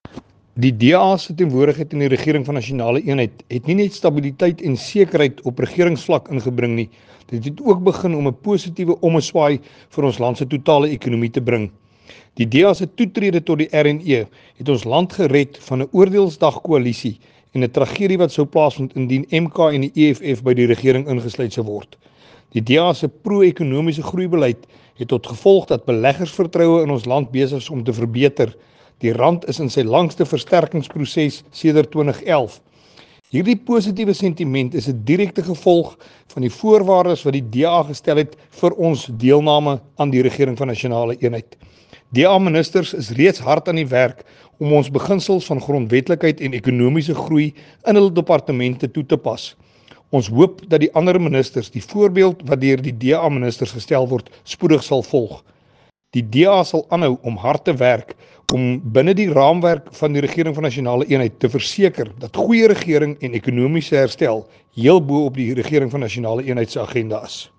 Issued by Willie Aucamp MP – DA Spokesperson
Note to editors: Please find attached soundbites in